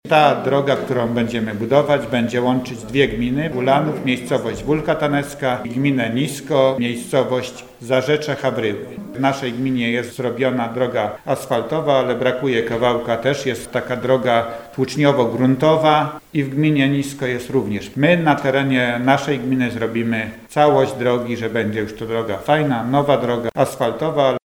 Burmistrz Ulanowa Stanisław Garbacz przyznał, że jest to ważna droga nie tylko dla mieszkańców Wólki, ale też droga łącząca gminę Ulanów z gminą Nisko w Zarzeczu-Hawryłach: